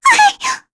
Lavril-Vox_Attack2_kr.wav